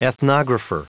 Prononciation du mot ethnographer en anglais (fichier audio)
Prononciation du mot : ethnographer